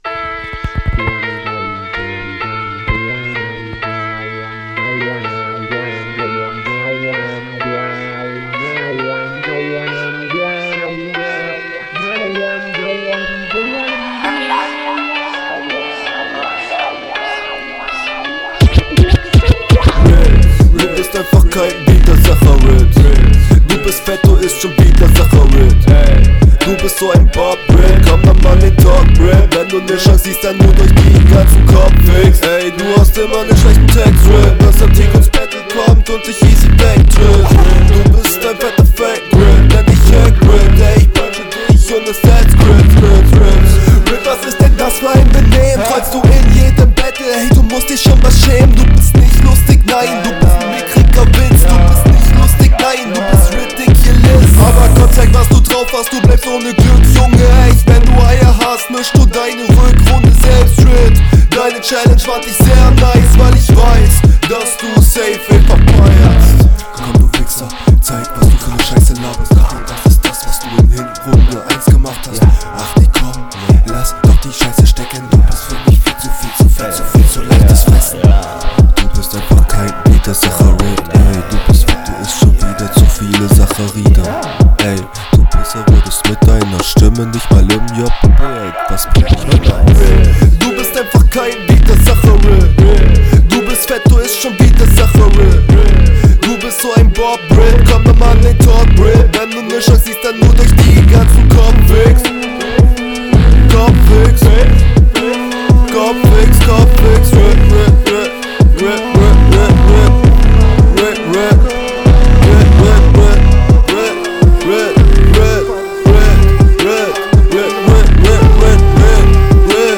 Beat passt ja super zu dir, gehst gar nicht unter.